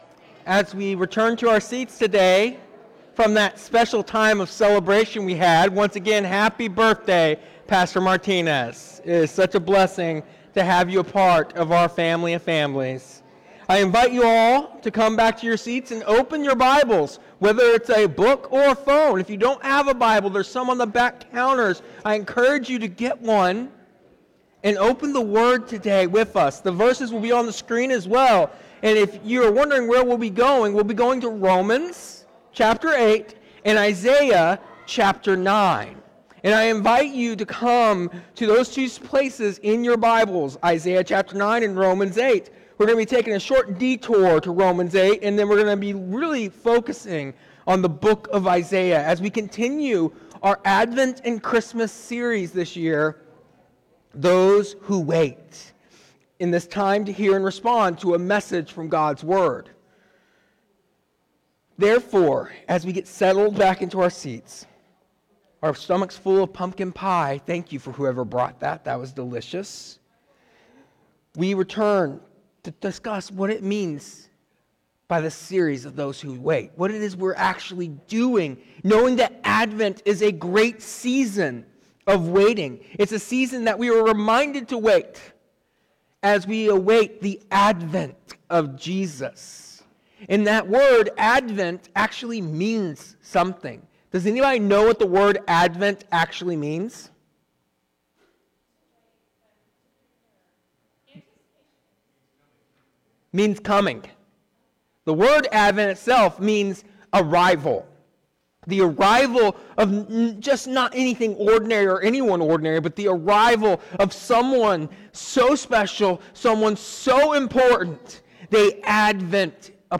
Today, we explore the theme of joy during Advent by reflecting on the message of Isaiah and the anticipation of Christ’s coming. We will emphasize the importance of waiting with expectation and a profound understanding that true joy is a choice rooted in faith, not fleeting emotions. The sermon will remind us that even in a world filled with suffering and darkness, the light of Jesus brings hope and the assurance of redemption.